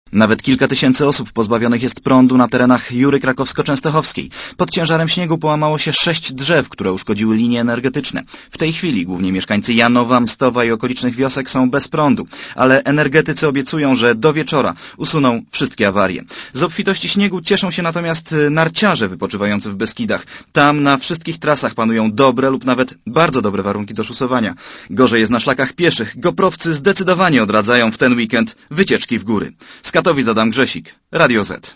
Relacja reportera Radia ZET Mamy uszkodzone w trzech miejscach linie 15 KV